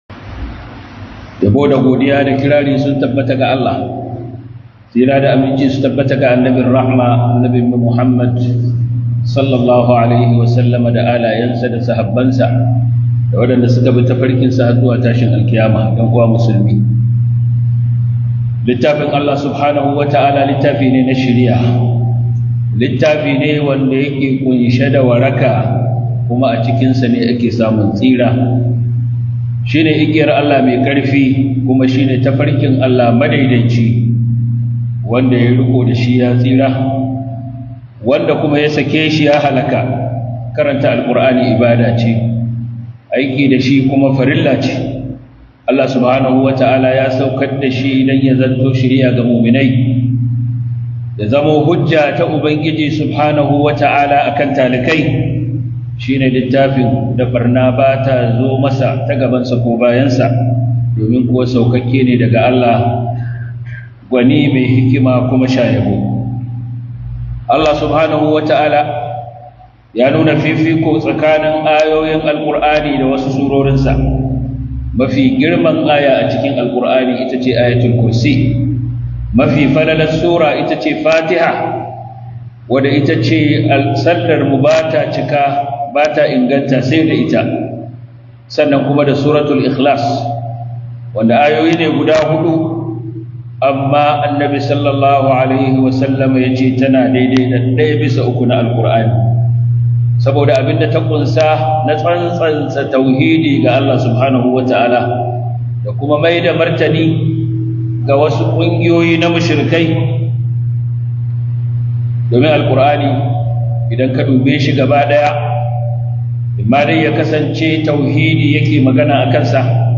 MARTANI GA MUSHIRIKAI (RADDI GA YAN BID’A) - HUDUBA